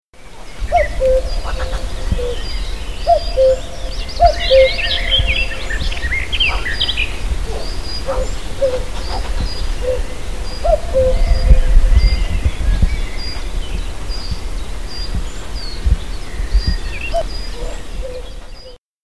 Den vierten Vogel trifft man hier auch recht häufig an.